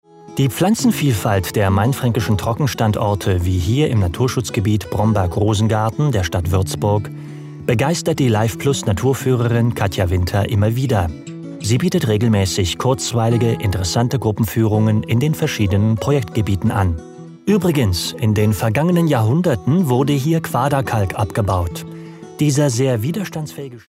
Souverän, Seriös, Selbstbewusst, Markant, Glaubwürdig, Vielseitig & Wandelbar, Sympathisch, mit Wiedererkennungswert
Kein Dialekt
Sprechprobe: Industrie (Muttersprache):
Believable, Versatile, Trusted, Characters, Young, Actor, Casual